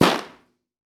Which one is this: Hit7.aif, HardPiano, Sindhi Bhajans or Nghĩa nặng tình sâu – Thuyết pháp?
Hit7.aif